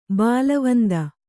♪ bālavanda